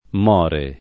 mU0Lono] — fut. morirò [morir0+] (lett. morrò [morr0+]): Noi morirem, ma non morremo inulti [noi morir%m, ma nnom morr%mo in2lti] (Tasso) — pres. cong. muoia [mU0La], moriamo [morL#mo], moriate [morL#te], muoiano [mU0Lano]; part. pass. morto [m0rto] — per muori, muore, pop. o poet. mori [m0ri], more [